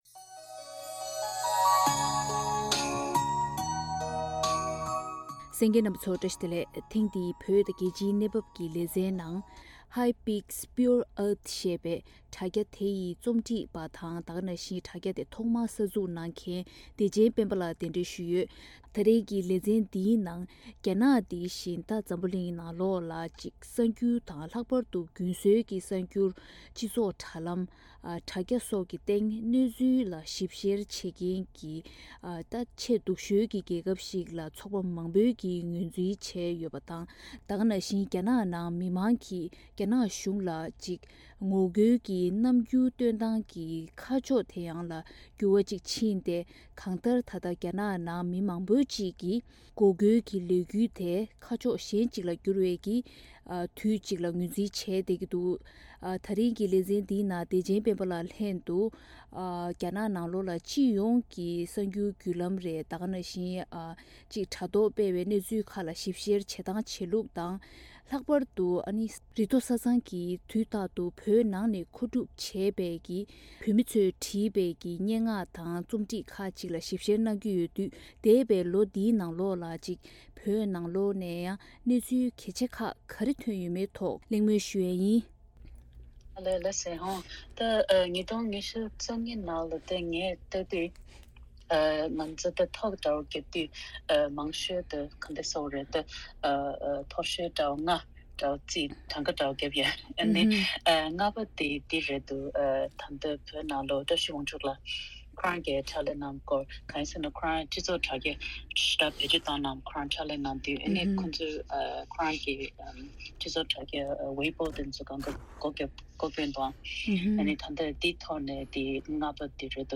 གླེང་མོལ་གནང་བ་གསན་རོགས།།